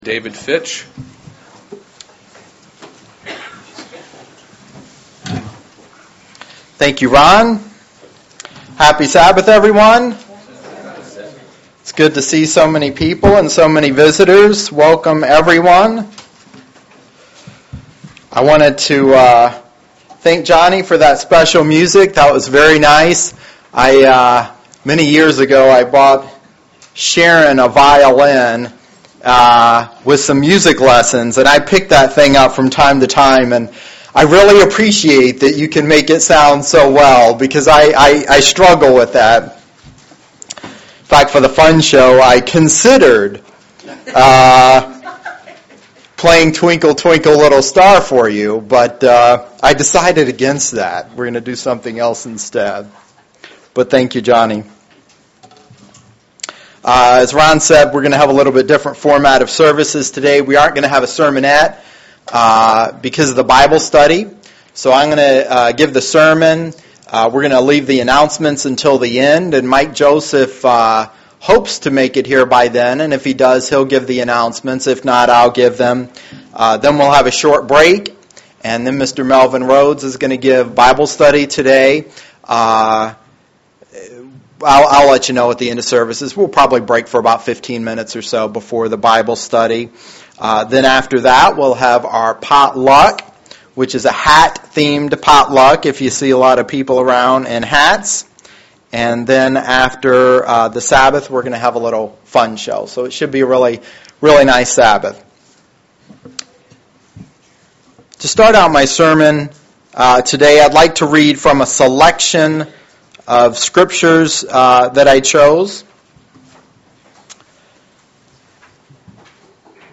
UCG Sermon Studying the bible?
Given in Lansing, MI